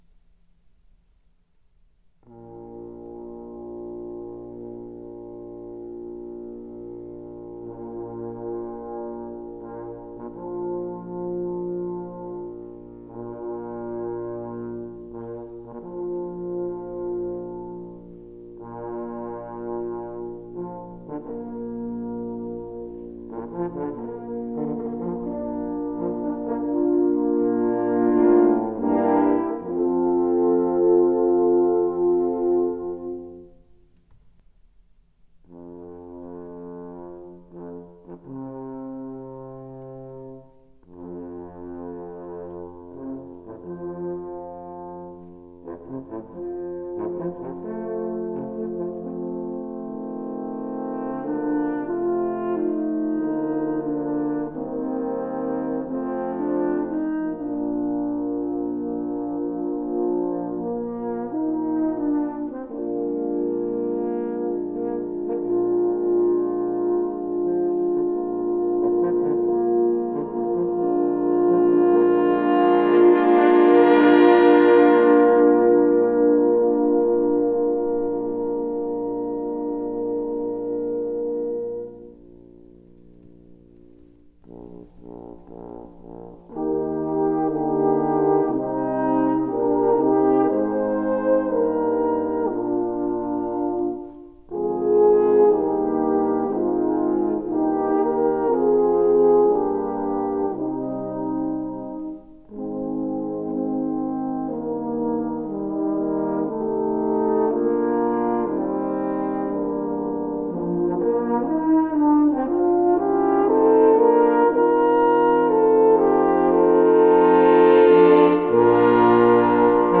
Bearbeitung für Hornensemble
Besetzung: 10 Hörner
Instrumentation: 10 horns